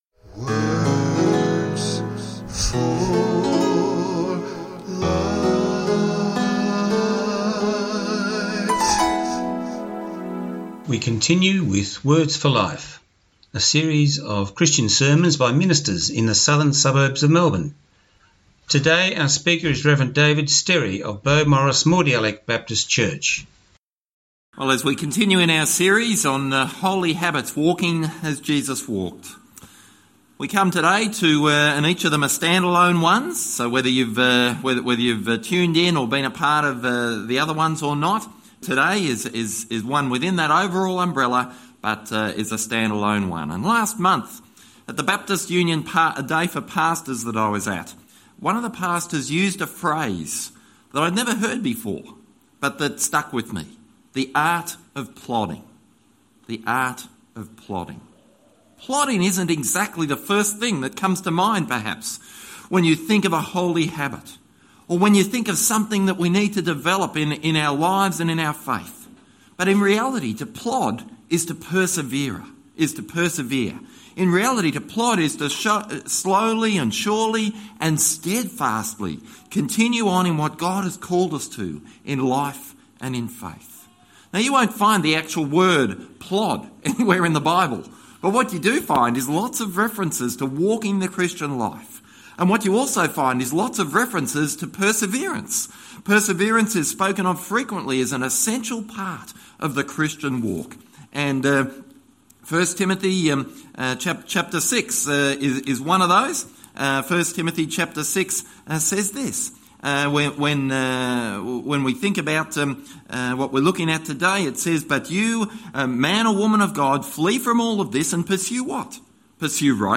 Talk time is 19 minutes.